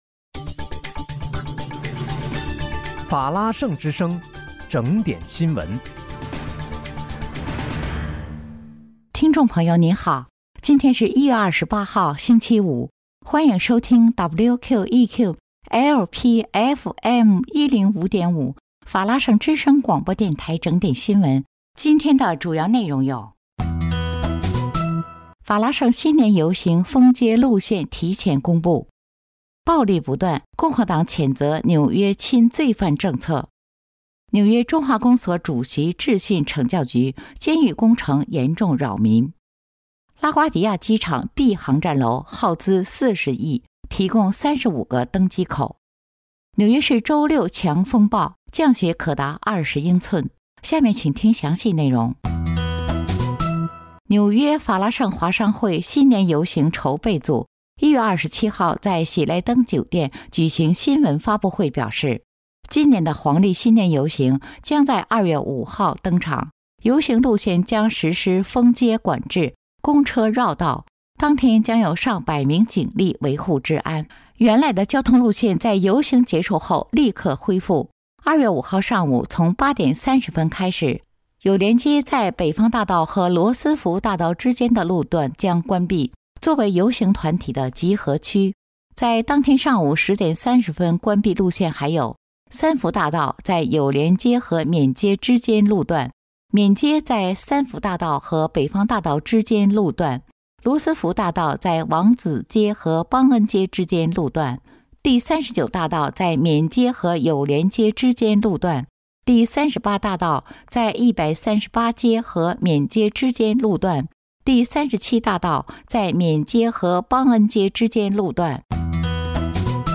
1月28日（星期五）纽约整点新闻